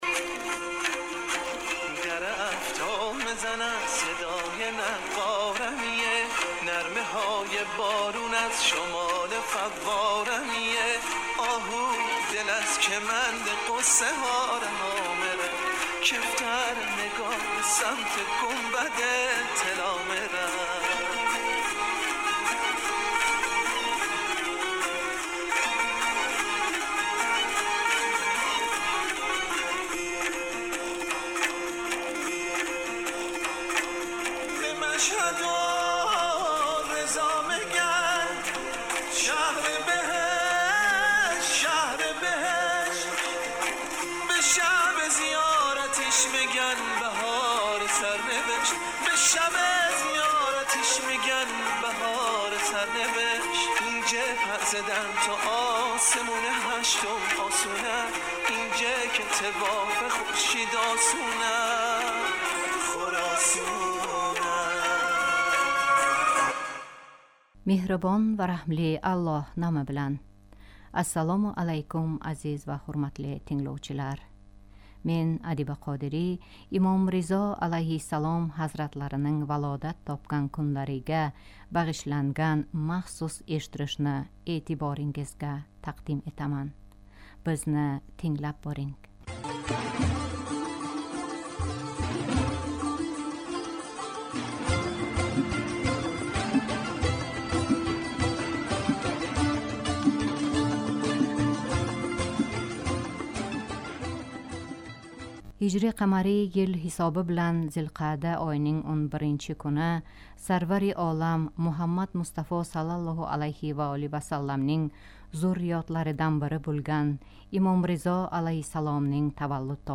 Имом Ризо алайҳиссалом ҳазратларининг валодат топган кунларига бағишланган махсус эшиттириш